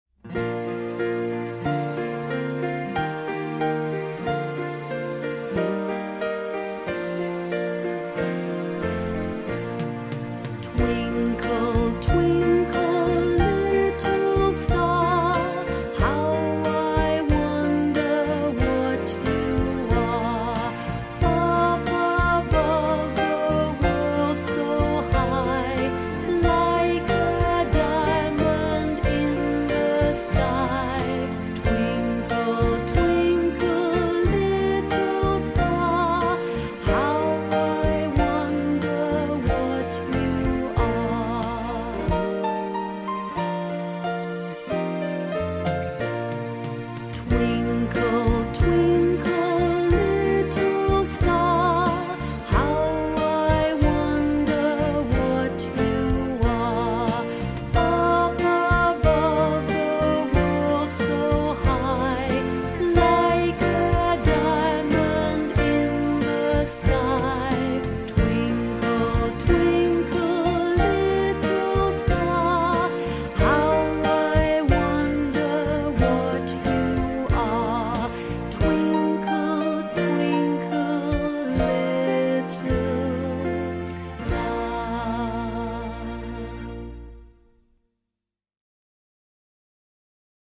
Twinkle Twinkle Little Star - английская песня-шутка - слушать онлайн